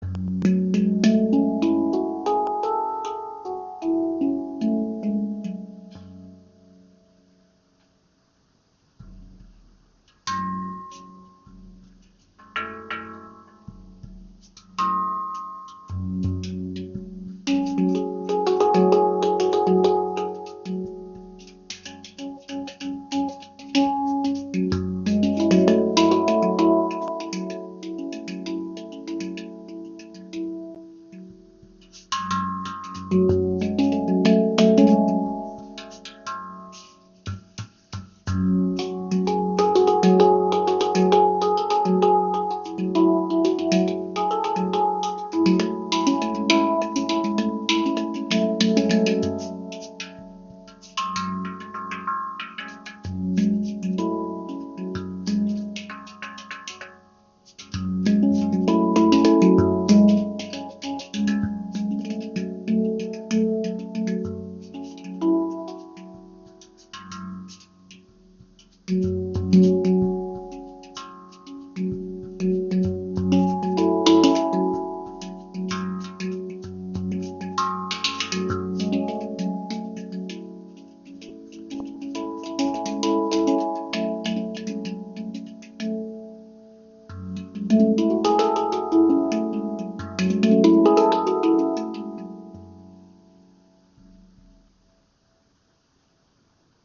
Handpan Noblet | F#2 Pygmy im Raven-Spirit WebShop • Raven Spirit
Klangbeispiel
Alle Klangfelder dieser Noblet Handpan in der Stimmung F# Pygmy sind sehr gut gestimmt und lassen sich auch mit fortgeschrittenen Spieltechniken anspielen.
Pygmy's- sind speziell, da mehrere Töne jeweils eine Oktave höher noch einmal vorhanden sind und sich so die Obertöne gut verstärken.
Handpans mit einer Moll-Stimmung wie z.B. Pygmy klingen mystisch, verträumt, vielleicht auch etwas melancholisch.